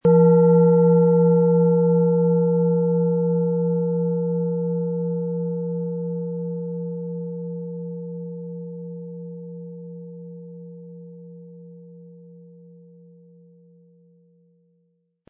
Tibetische Bauch-Schulter-Herz- und Kopf-Klangschale, Ø 15,7 cm, 400-500 Gramm, mit Klöppel
Tibetische Bauch-Schulter-Herz- und Kopf-Klangschale
Im Audio-Player - Jetzt reinhören hören Sie genau den Original-Ton der angebotenen Schale.
Der Klöppel lässt die Klangschale voll und harmonisch tönen.
MaterialBronze